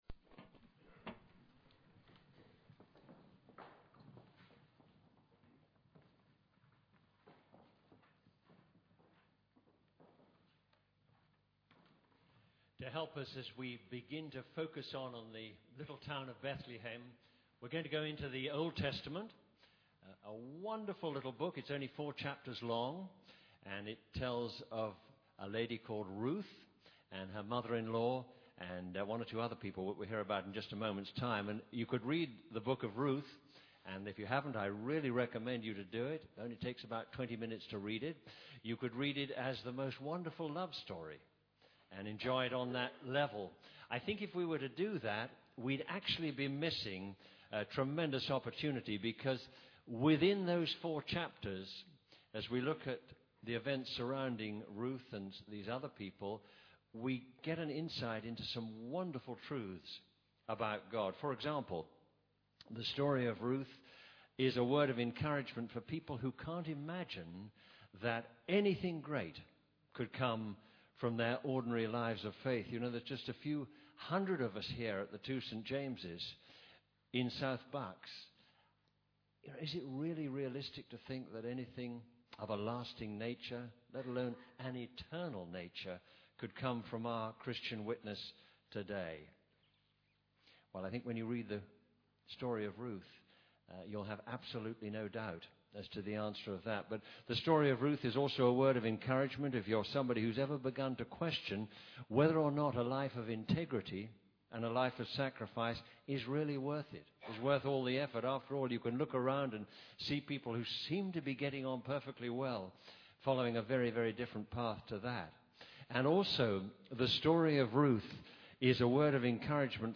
Latest Episode One Little Town Bethlehem - A new home (Ruth) Download the latest episode Note: in some browsers you may have to wait for the whole file to download before autoplay will launch. Talks given at the 10:30 Memorial Centre service Talks given at the St James 10:30 service held at the Memorial Centre, Gerrards Cross, Bucks, UK.